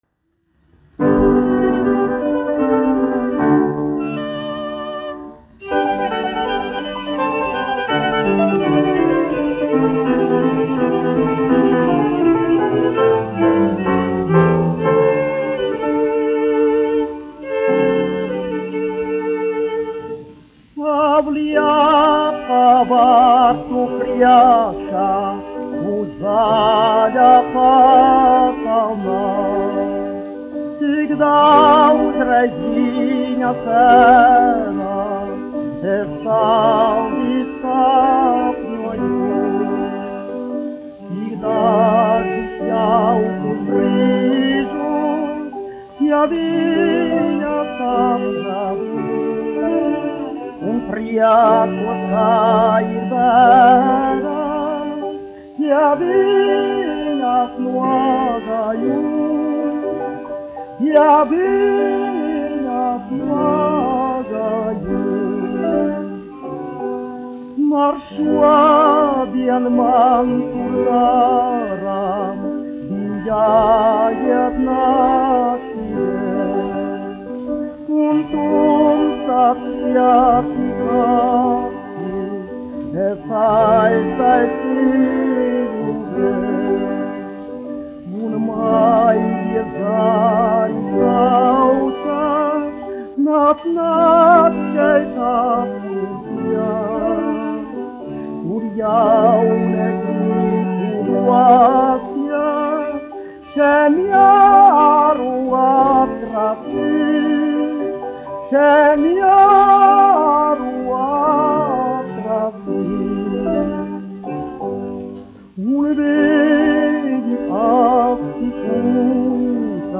1 skpl. : analogs, 78 apgr/min, mono ; 25 cm
Dziesmas (augsta balss) ar instrumentālu ansambli
Skaņuplate
Latvijas vēsturiskie šellaka skaņuplašu ieraksti (Kolekcija)